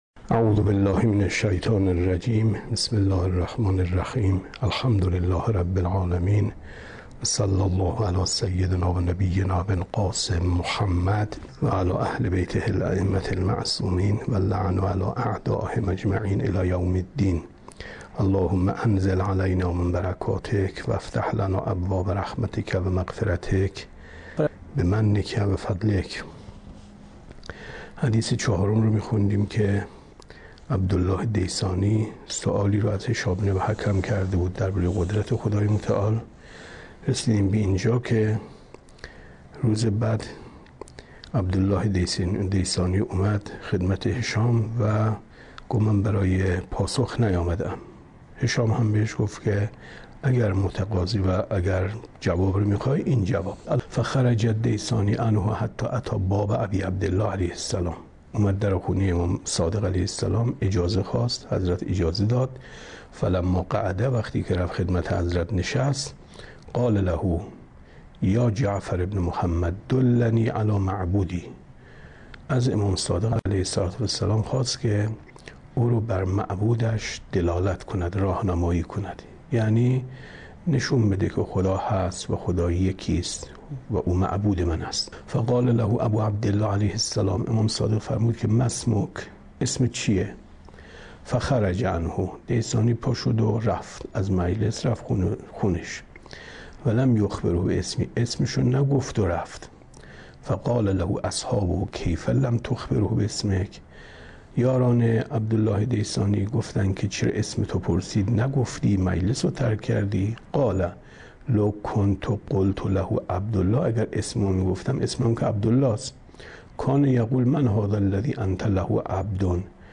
شرح اصول کافی درس 131 معنای اطلاق صفات خدای متعال